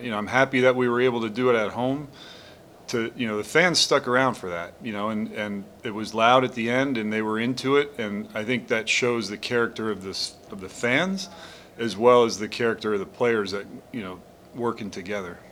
Royals mgr. Matt Quatraro on the walk-off win.